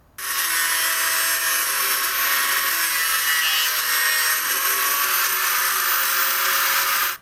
実際の騒音で比較
電ノコ